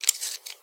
工具 剃刀片 Out02
描述：正在处理盒式切割机的声音。 此文件已标准化，大部分背景噪音已删除。没有进行任何其他处理。
Tag: 切割机 缩回 刀片 刀具 延伸 剃须刀